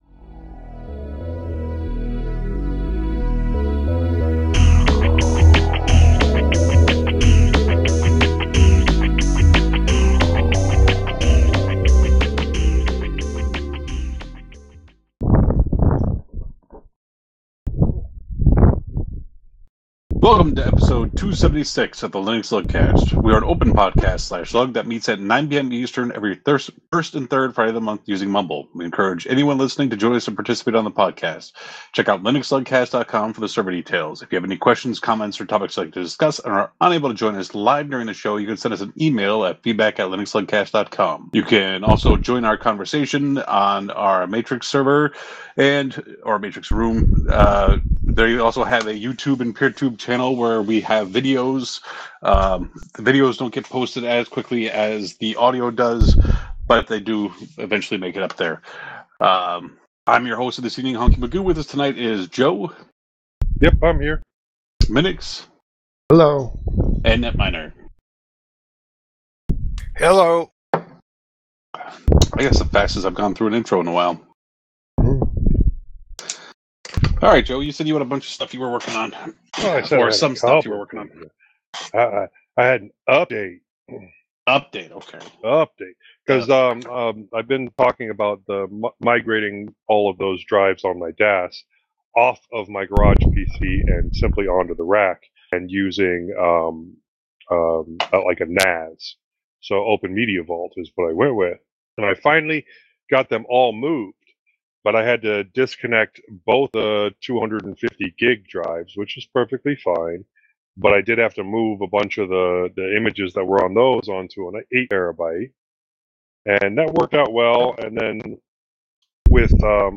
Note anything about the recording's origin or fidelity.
Welcome to Episode 288 of the LinuxLUGcast We are an open podcast/LUG that meets at 9 PM EST every first and third friday of the month using mumble.